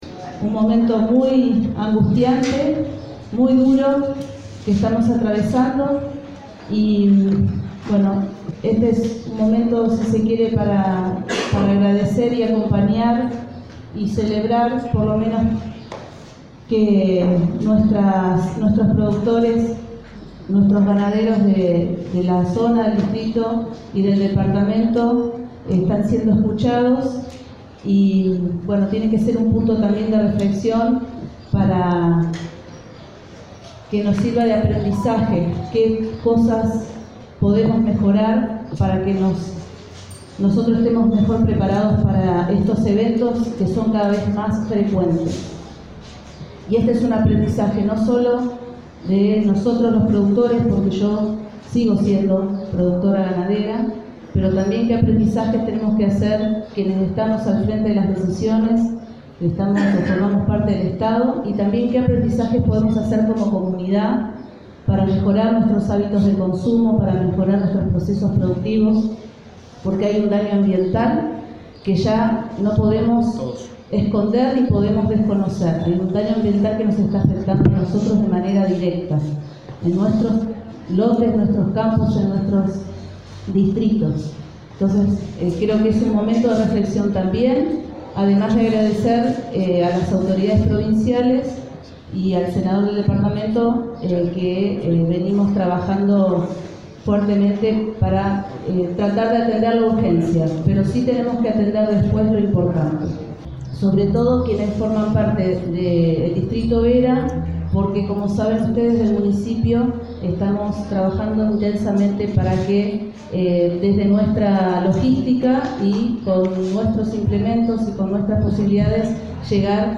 Intendenta de Vera, Paula Mitre